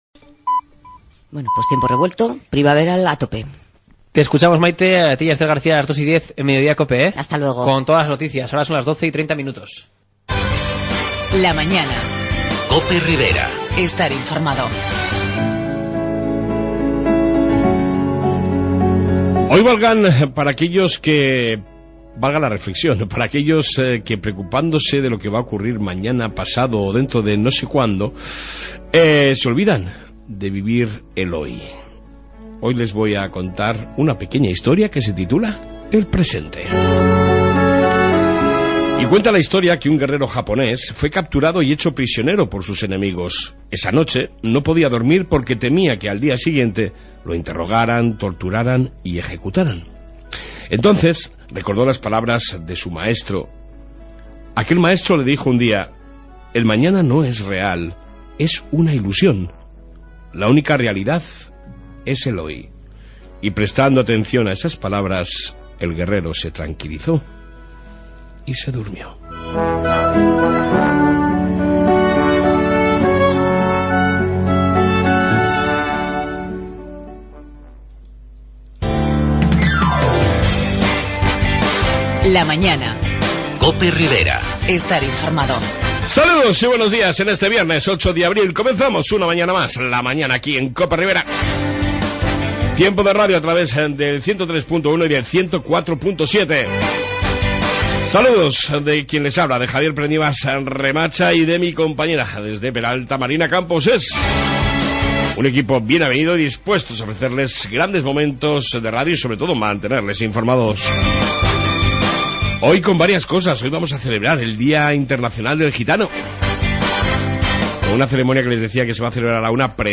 AUDIO: En esta 1ª parte Reflexión diaria,Info policía Municipal, Entrevista sobre el día Internacional del mundo gitano y Conectamos...